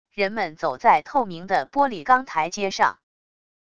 人们走在透明的玻璃钢台阶上wav音频